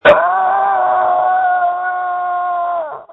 Screams from December 24, 2020
• When you call, we record you making sounds. Hopefully screaming.